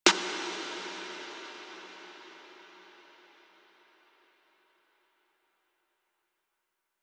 Waka SNARE ROLL PATTERN (65).wav